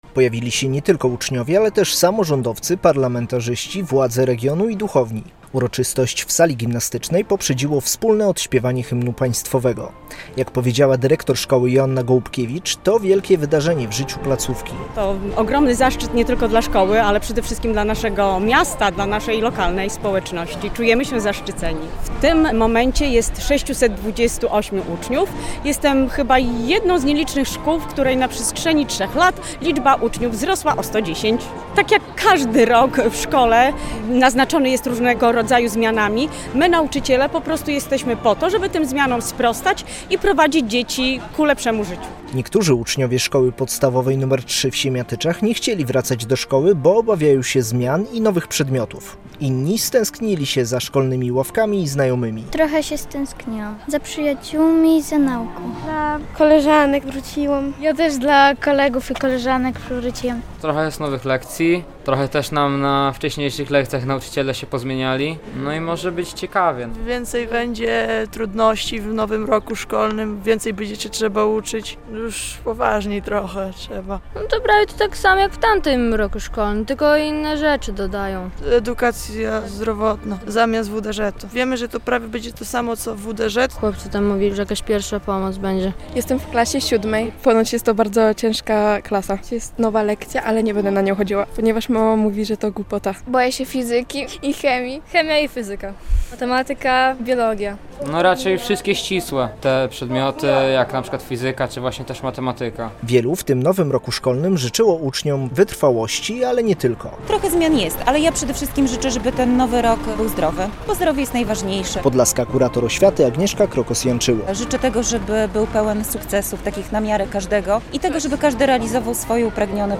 Wojewódzkie rozpoczęcie nowego roku szkolnego w Siemiatyczach - relacja